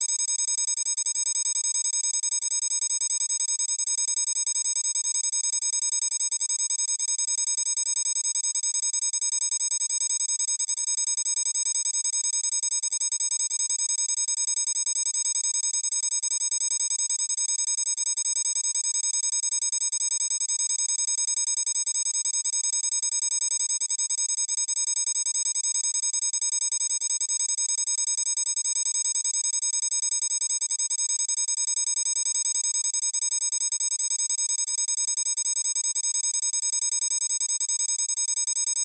高い音。